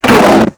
ALIEN_Communication_24_mono.wav